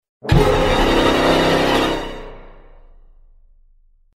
7. Смерть персонажа амонг ас